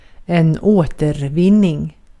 Ääntäminen
Ääntäminen US Haettu sana löytyi näillä lähdekielillä: englanti Käännös Ääninäyte Substantiivit 1. återvinning {en} 2. retur {en} Recycling on sanan recycle partisiipin preesens.